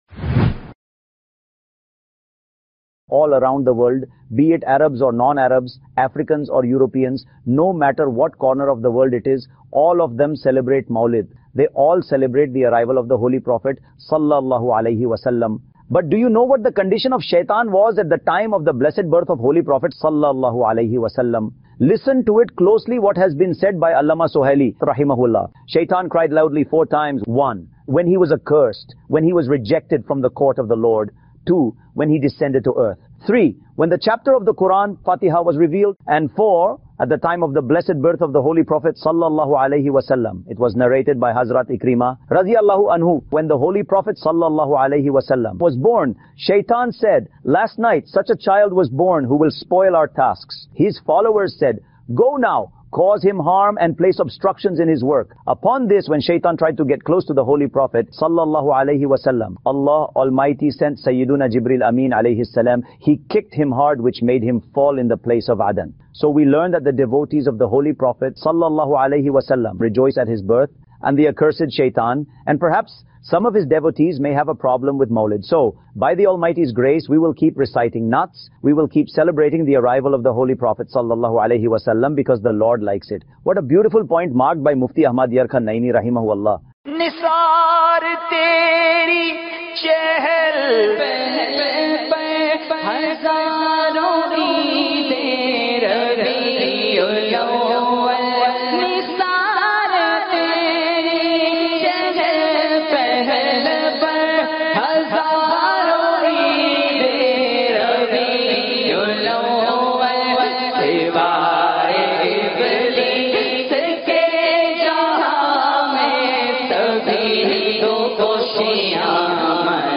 Satan's Condition When The Holy Prophet صلی اللہ علیہ واٰلہ وسلم Was Born (AI-Generated) Sep 26, 2024 MP3 MP4 MP3 Share Satan's Condition When The Holy Prophet صلی اللہ علیہ واٰلہ وسلم Was Born (AI-Generated)